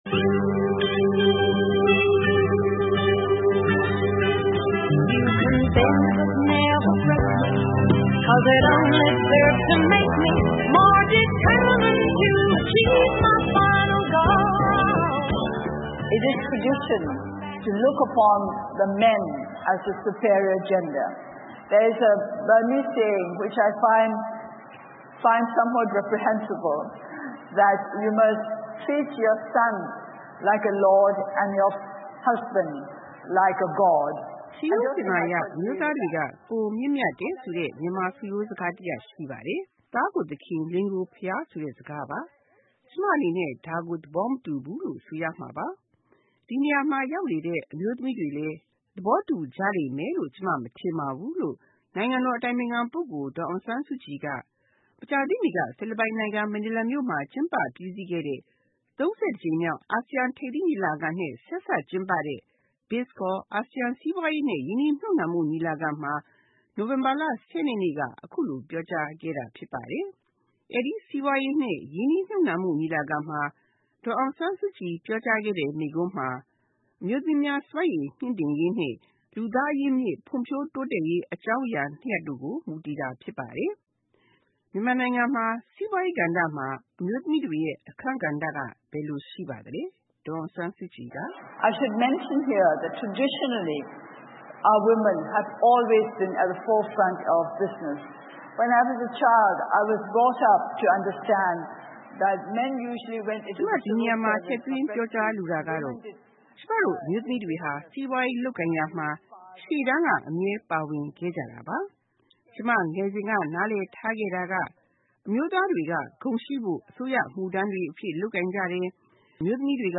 ဖိလစ်ပိုင်နိုင်ငံ၊ မနီလာမြို့တော်မှာ ကျင်းပခဲ့တဲ့ ၃၁ ကြိမ်မြောက် ASEAN ညီလာခံနဲ့ ဆက်စပ်ကျင်းပခဲ့တဲ့ ASEAN စီးပွားရေးနဲ့ ရင်းနှီးမြှုပ်နှံမှု ဆိုင်ရာ ညီလာခံမှာ နိုင်ငံတော်အတိုင်ပင်ခံပုဂ္ဂိုလ် ဒေါ်အောင်ဆန်းစုကြည်က မြန်မာနိုင်ငံက အမျိုးသမီးများ စွမ်းဆောင်ရည် မြှင့်တင်ပေးရေးနဲ့ လူသားအရင်းအမြစ် ဖွံ့ဖြိုးတိုးတက်ရေးအကြောင်း မိန့်ခွန်းပြောကြားခဲ့ပါတယ်။